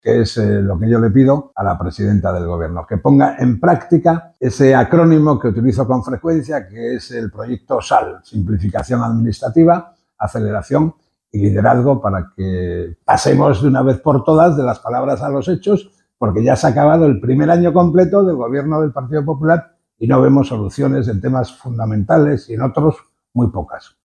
Ver declaraciones de Francisco Javier López Marcano diputado del Partido Regionalista de Cantabria y portavoz del PRC en materia de empleo.